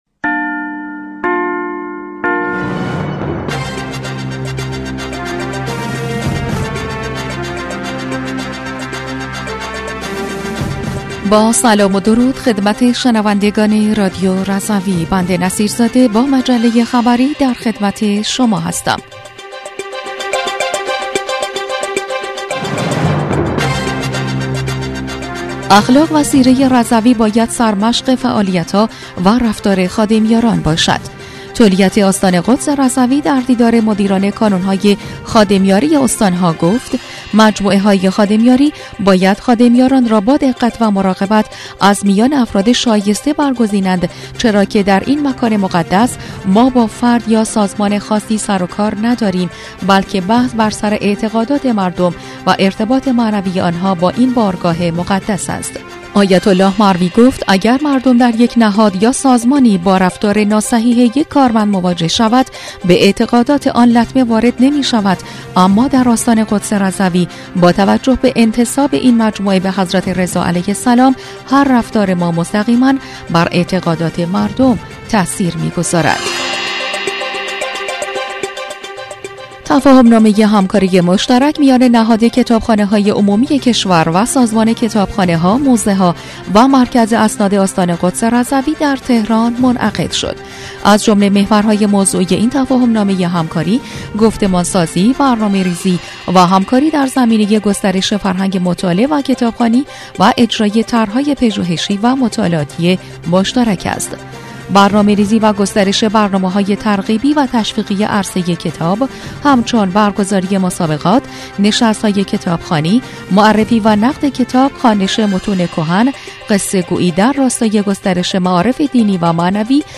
بسته خبری ۲۹ اردیبهشت‌ماه رادیو رضوی/